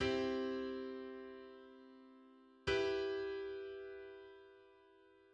Contrast chord example
Play: C major and E minor contrast through their respective notes C and B (in red and orange), each a half step apart or leading tones. The chords share two notes (in blue) however.
Contrast_chord_example.mid.mp3